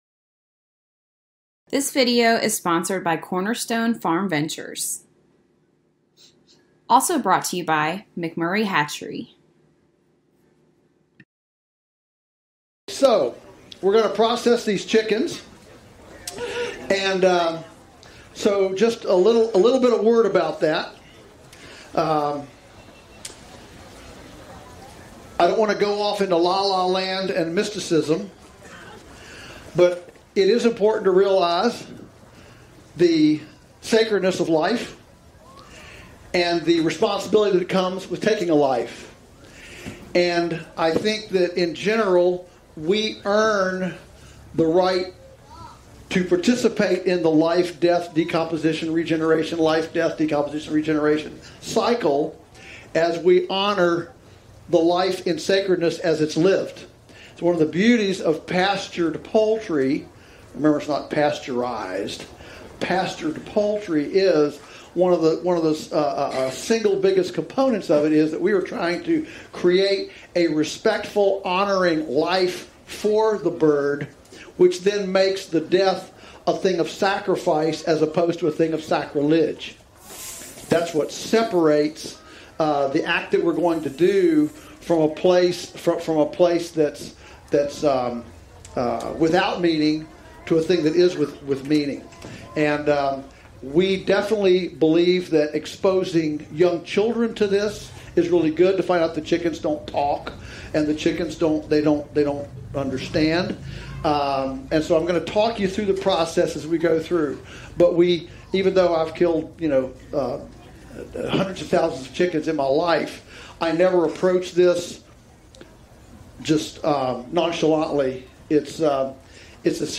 Joel Salatin - Broiler Processing Demo Homesteaders of America 2019